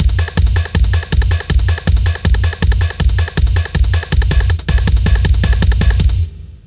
drumschorus.aiff